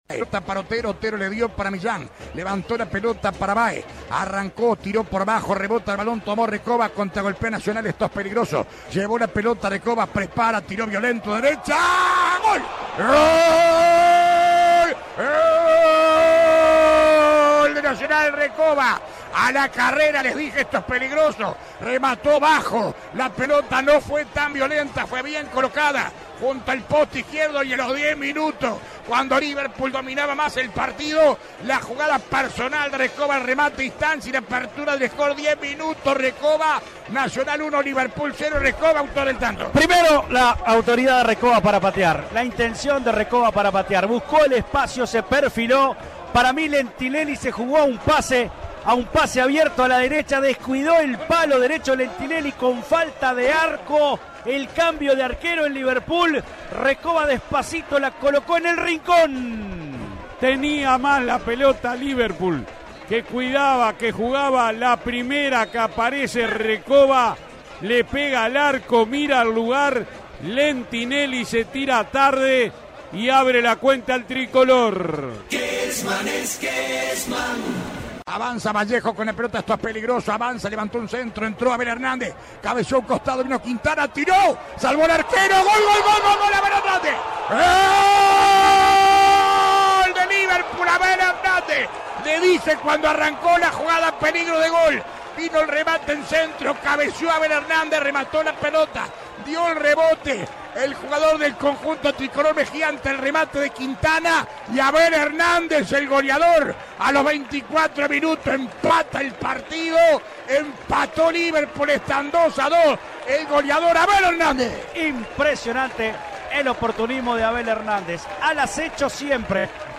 LOS GOLES RELATADOS EN LA VOZ DE ALBERTO KESMAN